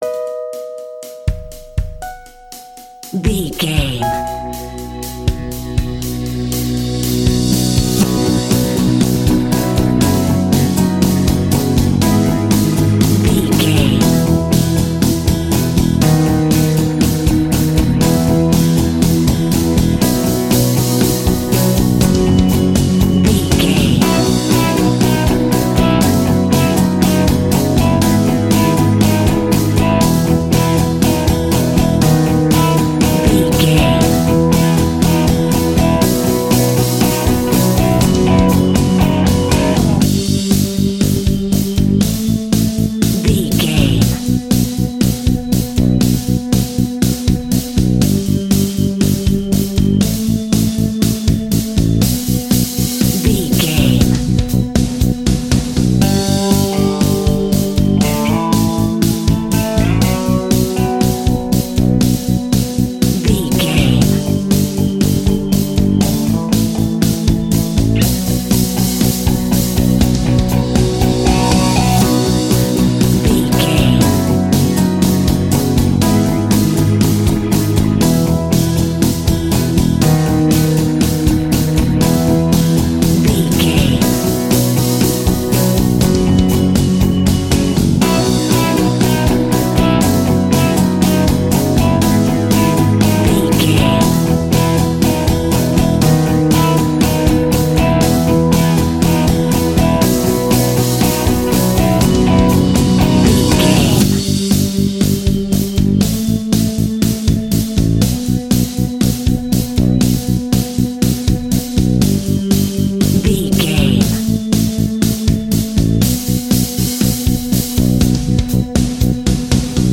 A great piece of royalty free music
Ionian/Major
C#
pop rock
indie pop
energetic
uplifting
upbeat
groovy
guitars
bass
drums
piano
organ